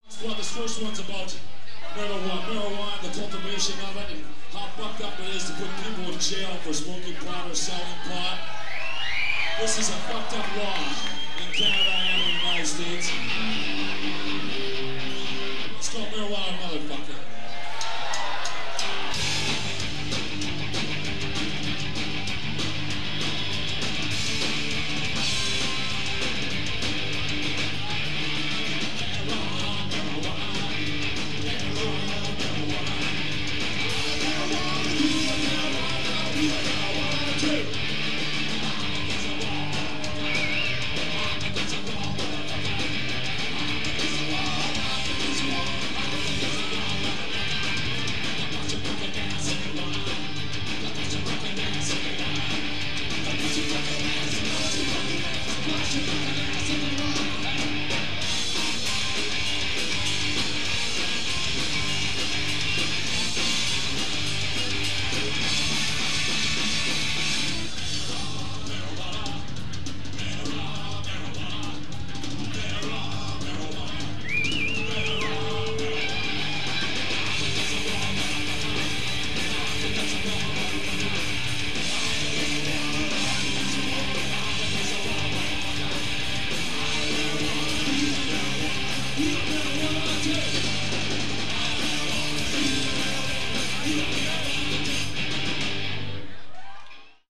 punk rock
(live)